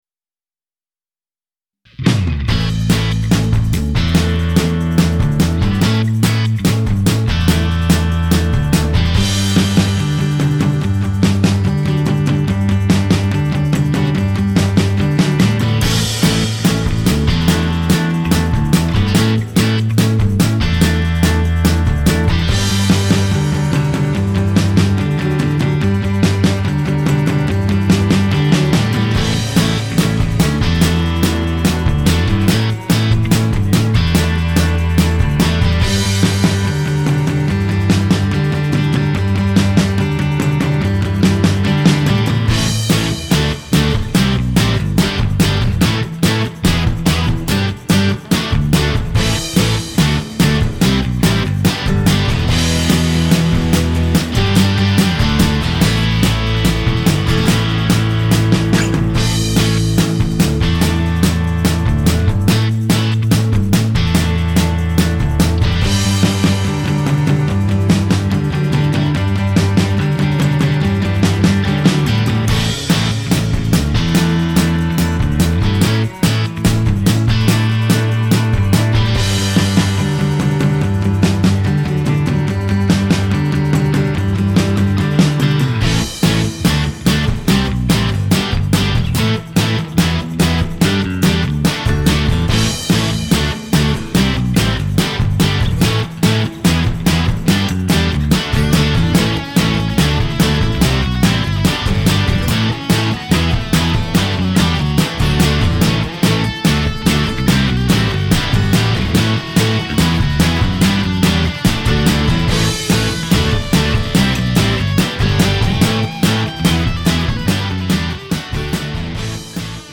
This took two after replacing drum patterns with a played part.
It's not perfect or mixed particularly well and I guess the arrangement is kinda hokey and it needs singing but I think it has a good retro vibe.